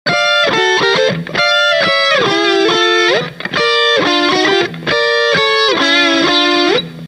Guitar Fender STRTOCASTER
Amplifier VOX AD30VT AC30TB